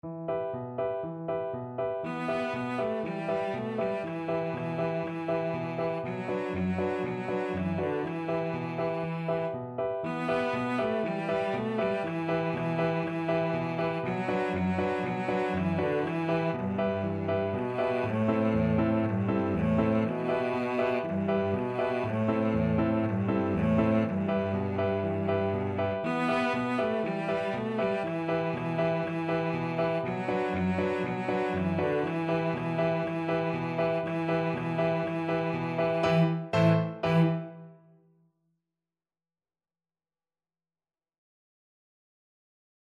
Allegro vivo (View more music marked Allegro)
4/4 (View more 4/4 Music)
World (View more World Cello Music)